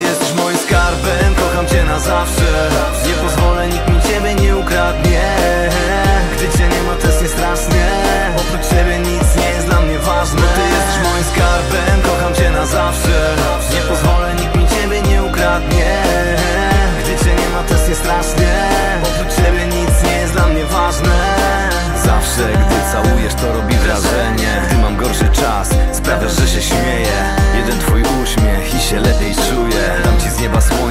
Kategoria POP